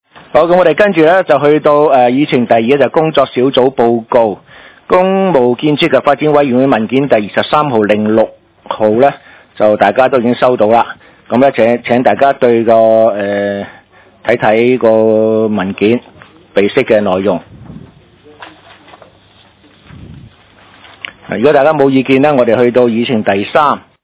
東區法院大樓11樓東區區議會會議室